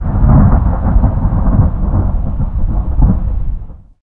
thunder24.ogg